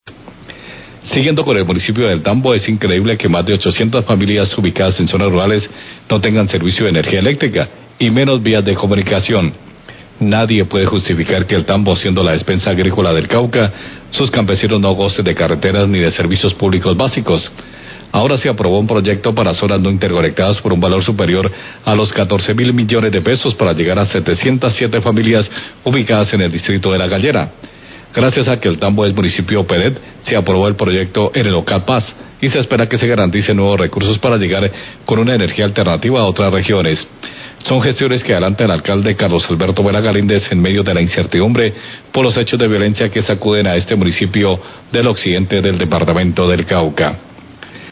Columna opinión sobre proyecto de energía a familias de El Tambo por el Ocad Paz
Radio